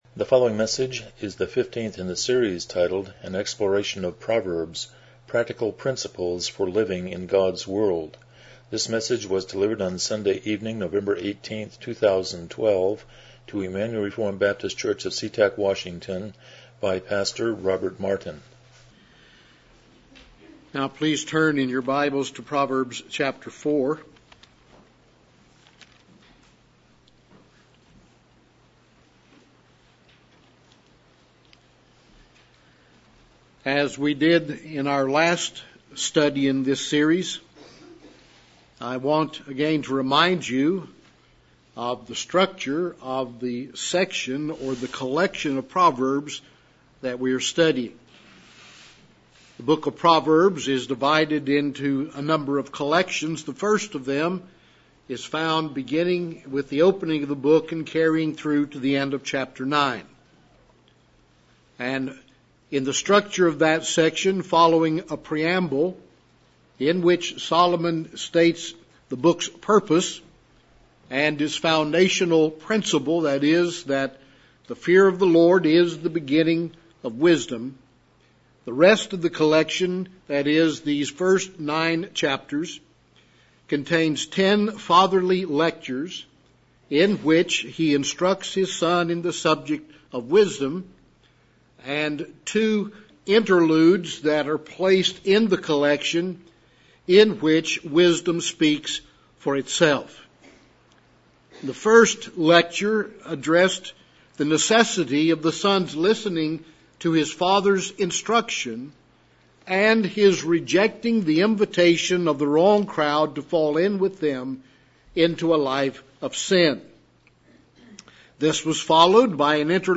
Proverbs 4:10-19 Service Type: Evening Worship « 18 The Sermon on the Mount